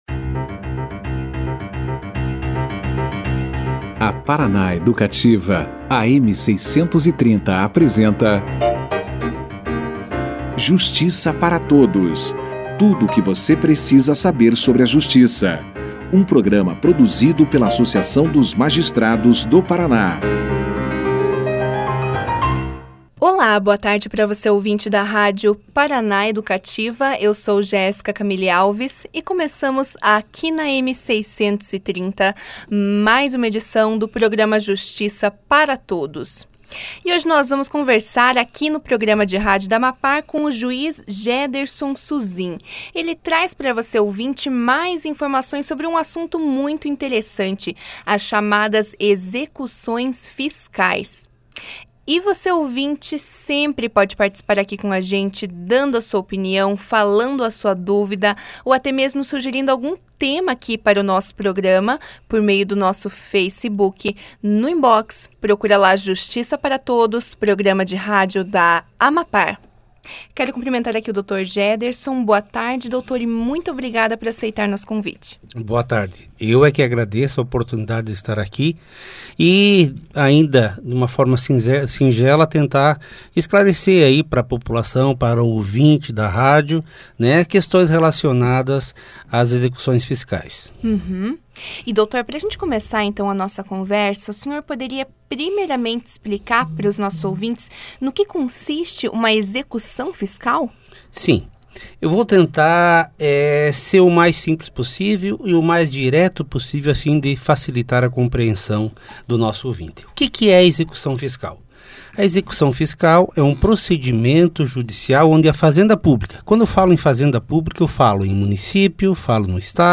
O Juiz de Direito Jederson Suzin participou do programa de rádio da Associação dos Magistrados do Paraná - AMAPAR, o Justiça para Todos, na quinta-feira (4), para falar sobre execuções fiscais. Logo no início da entrevista, o magistrado explicou aos ouvintes no que consistem as chamadas execuções fiscais - apontando suas etapas e esclarecendo o que é uma certidão de dívida ativa e se há a possibilidade do contribuinte discutir essa dívida.